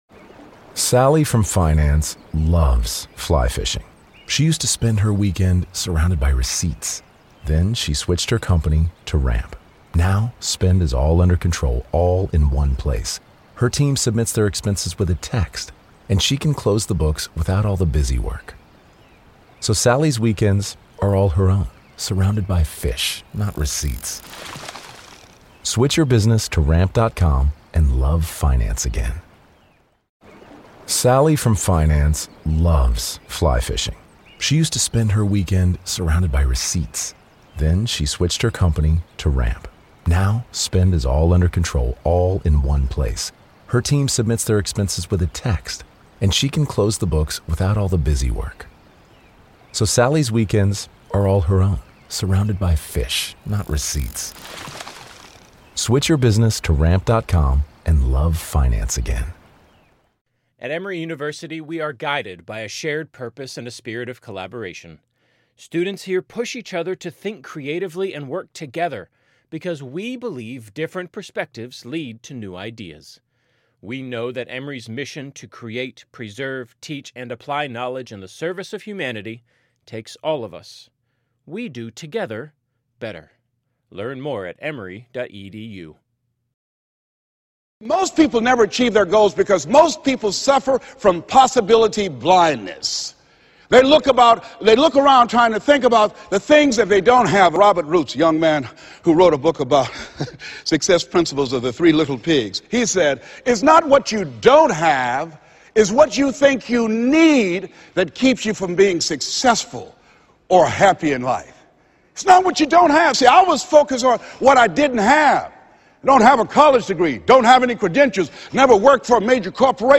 Unleashing the Power Within to Achieve Your Goals - Powerful Motivational Speech